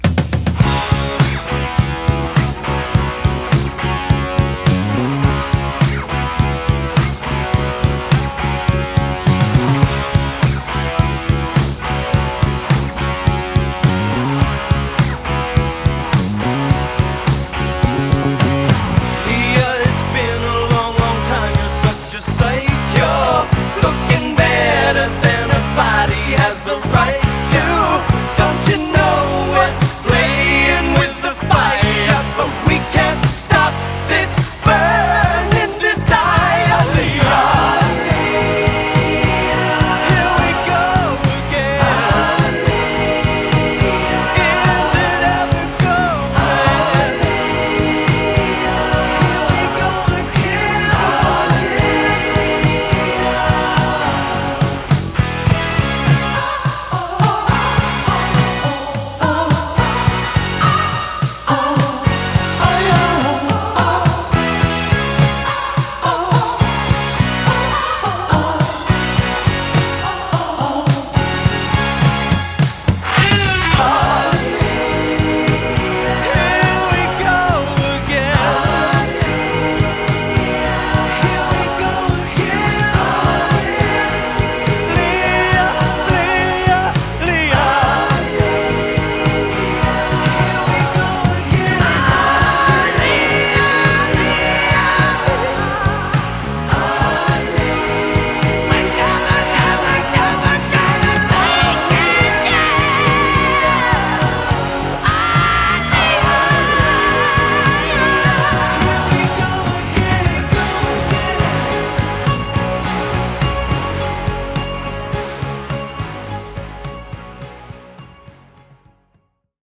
BPM104-106
MP3 QualityMusic Cut